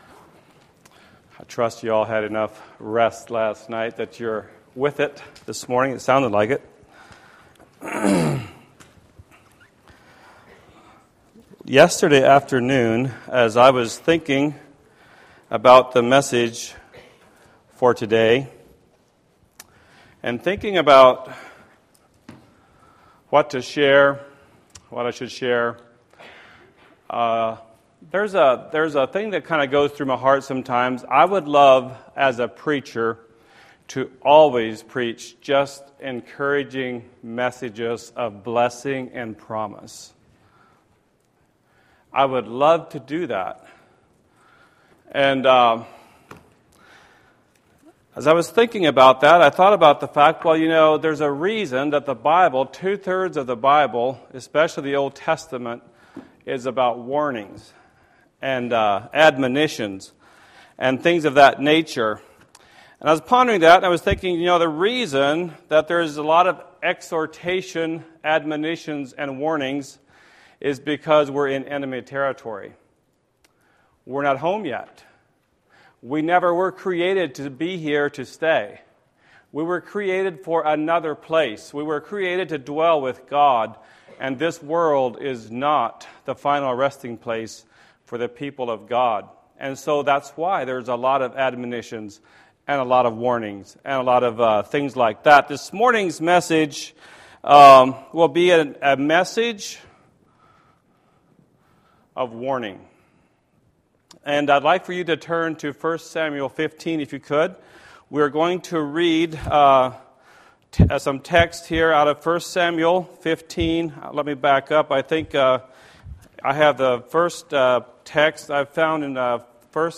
Series: Navigating A Godless Society; Embracing Godly Convictions, Youth Bible School 2021